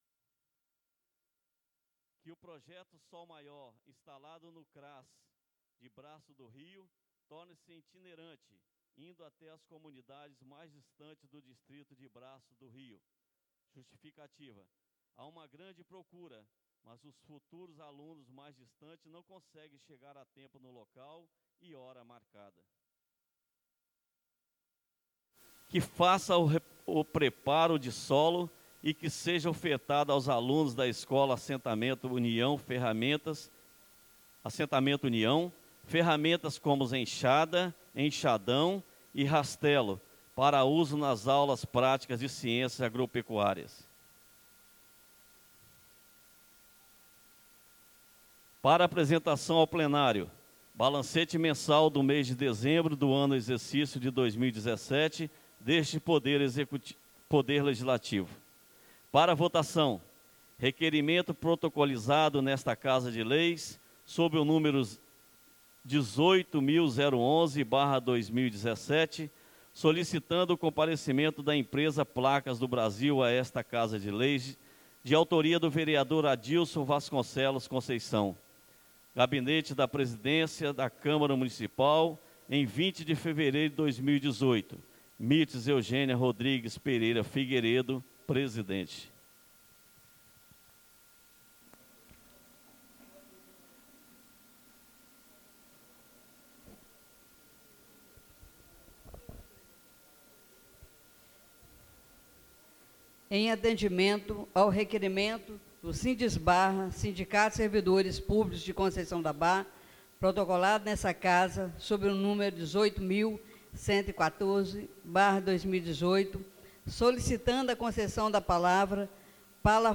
1ª ( PRIMEIRA) SESSÃO ORDINÁRIA DIA 22 DE FEVEREIRO DE 2017 BRAÇO DO RIO